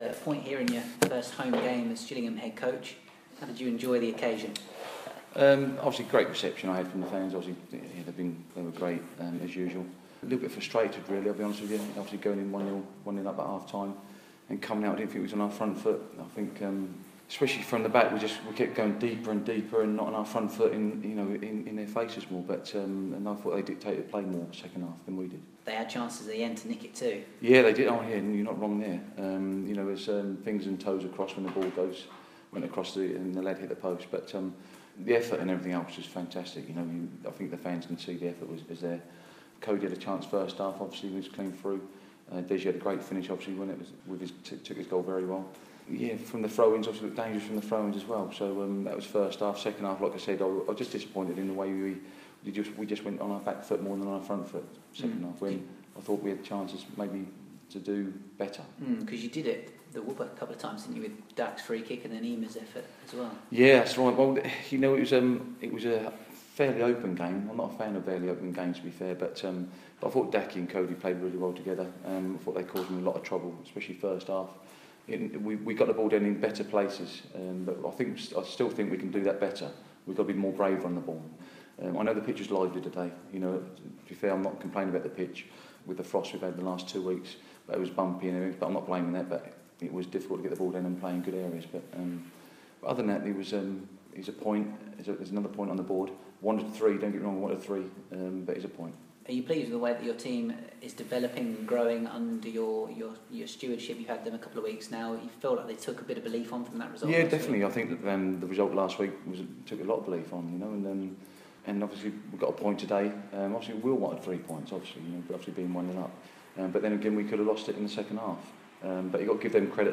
Recorded post match - 28th January 2017